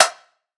Perc  (3).wav